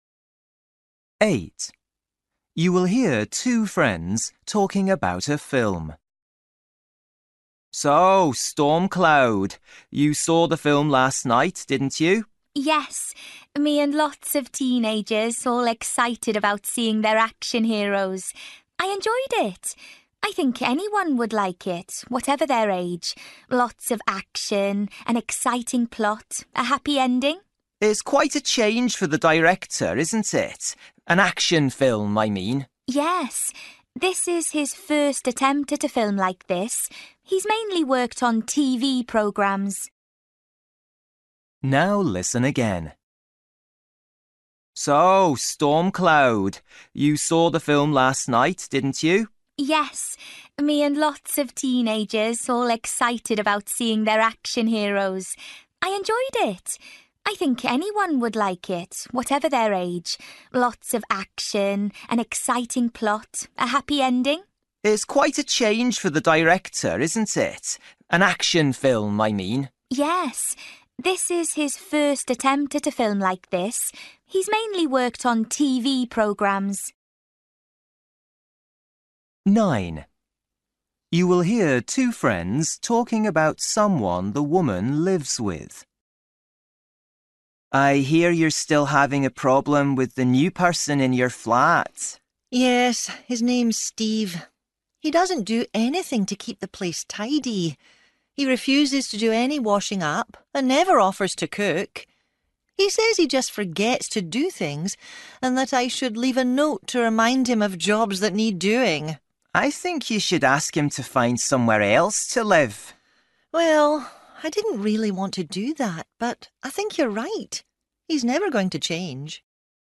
Listening: everyday short conversations
You will hear two friends talking about a film. What does the woman say about it?
10   You will hear a woman telling a friend about a holiday she is going on. How does she feel about it?